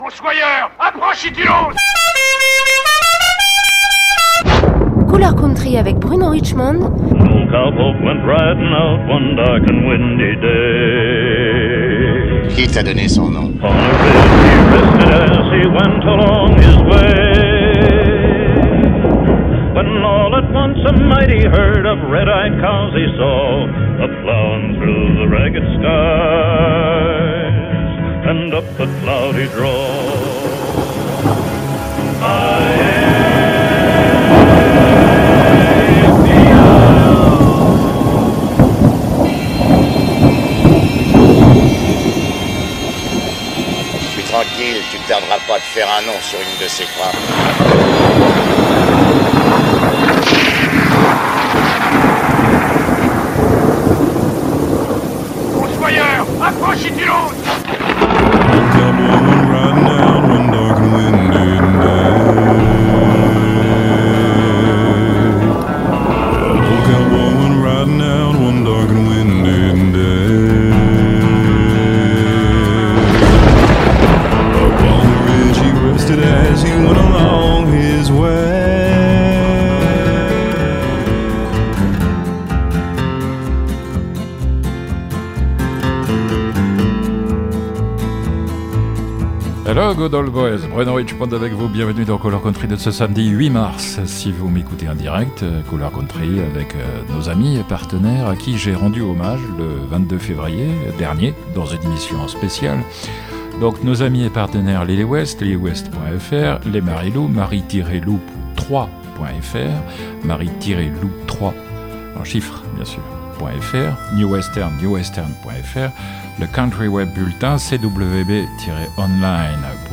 Le direct